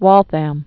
(wôlthăm, -thəm)